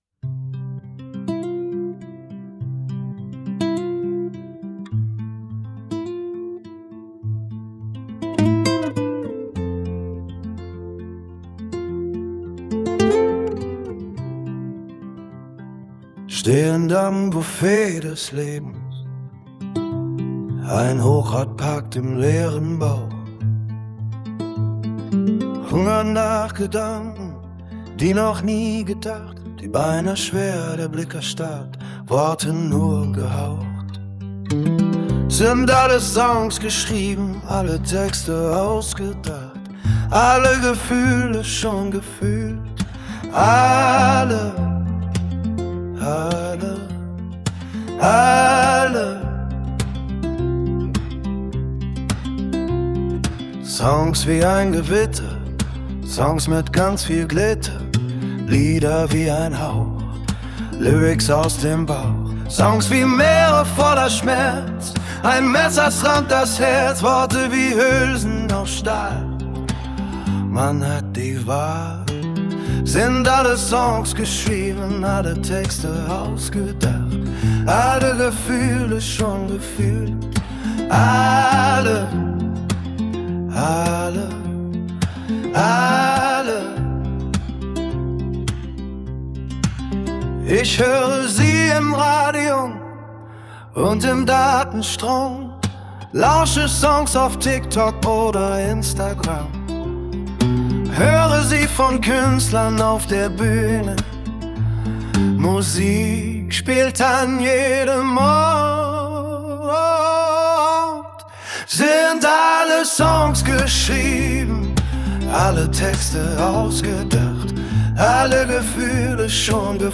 “Alle Songs” – Deutsch, männlicher Singer-Songwriter, Emotional, Akustische Gitarre
Alle-Songs_gitarre.mp3